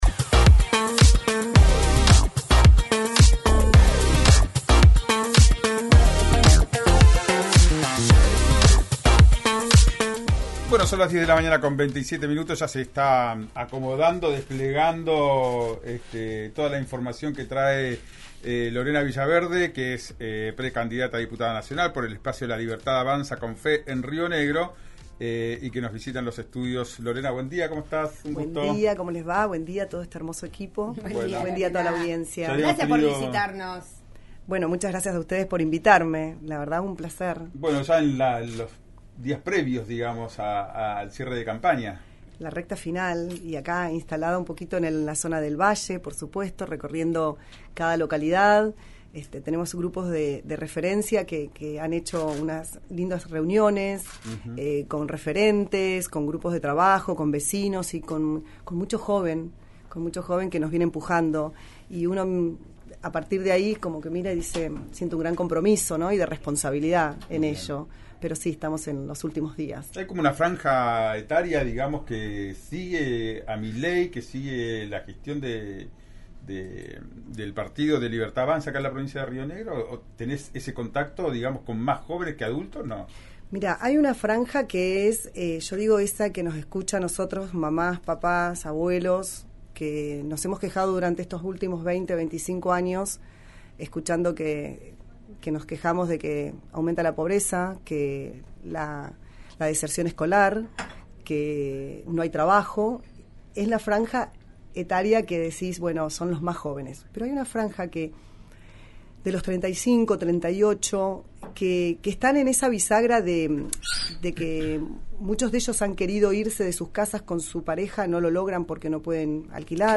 En sus últimos días de campaña y previo a llegar a Bariloche, Villaverde pasó por los estudios del programa “Ya es Tiempo” que se emite por RÍO NEGRO RADIO, donde aseguró que Milei no sólo logró captar el voto de los jóvenes sino también de aquellas personas que superan los 35 años y que tienen que vivir con sus padres porque no les alcanza para pagar un alquiler.